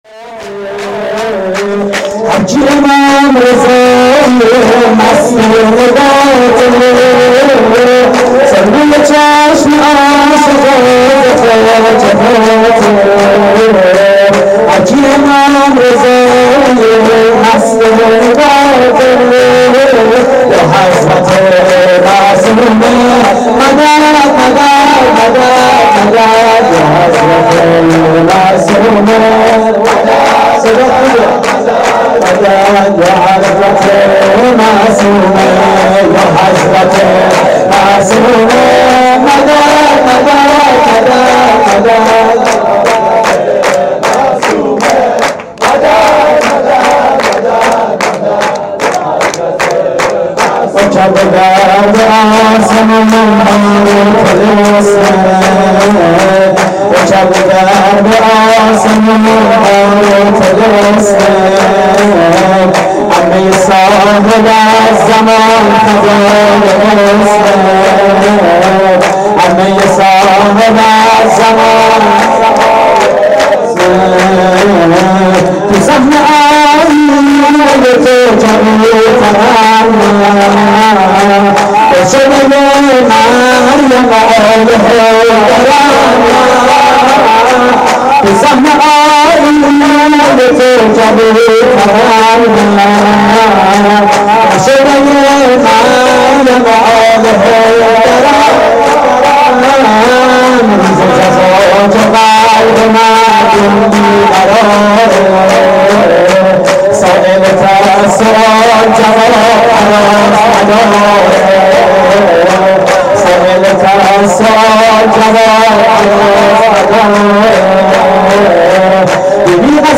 شور: یا حضرت معصومه
مراسم جشن ولادت حضرت معصومه (س)